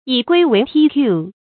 以规为瑱 yǐ guī wéi tiàn
以规为瑱发音
成语注音ㄧˇ ㄍㄨㄟ ㄨㄟˊ ㄊㄧㄢˋ